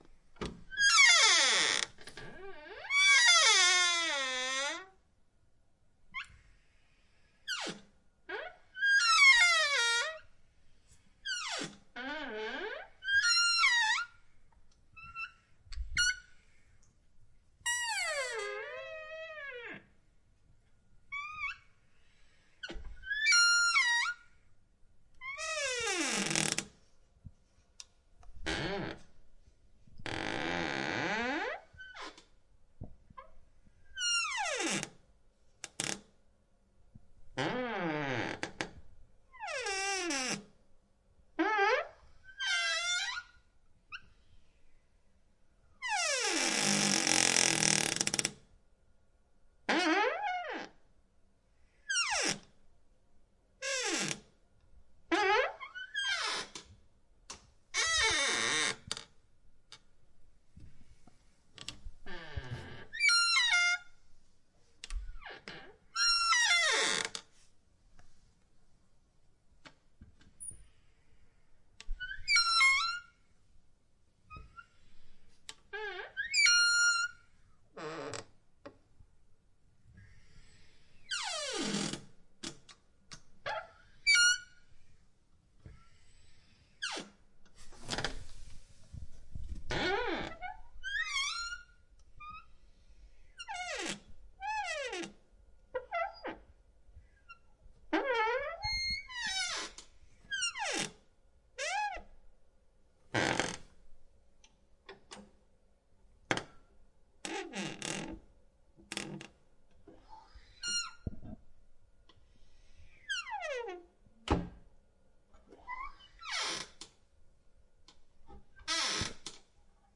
随机 " 门的木质铰链吱吱作响，打开关闭
描述：门木铰链吱吱声打开close.flac
Tag: 打开 吱吱 吱吱声 关闭 木材 合页